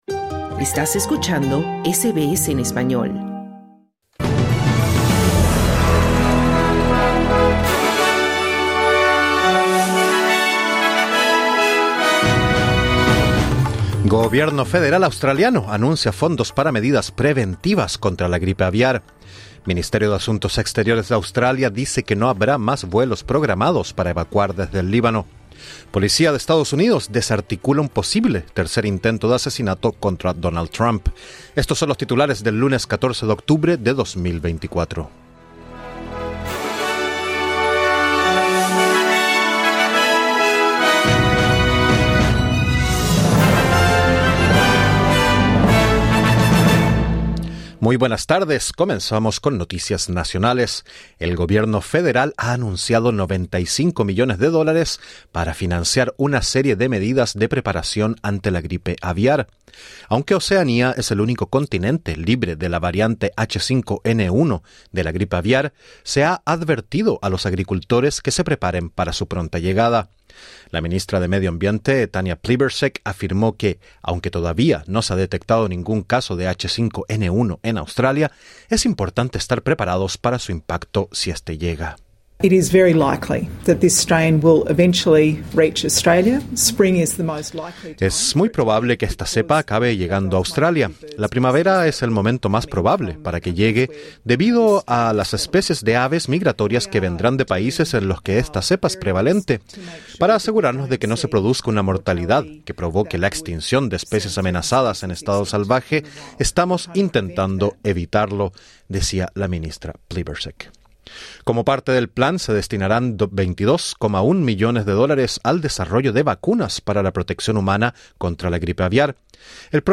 Escucha el boletín en el podcast localizado en la parte superior de esta página.